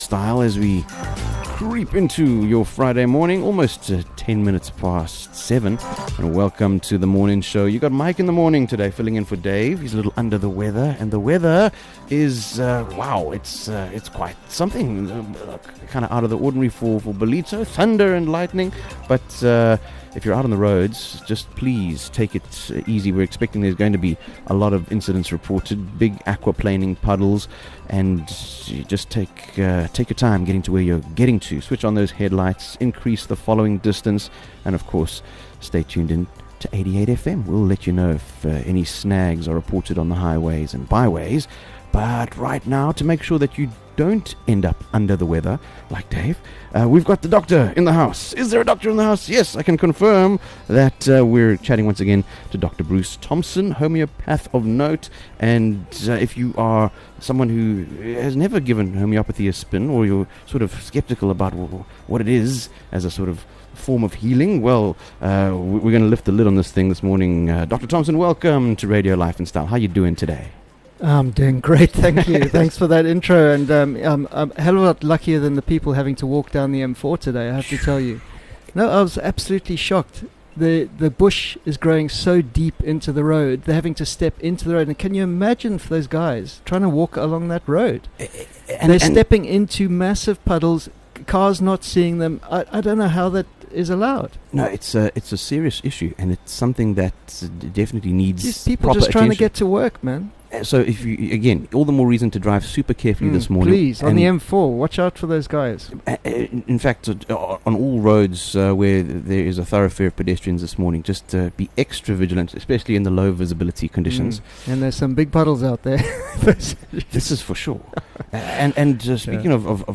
Join us as we interview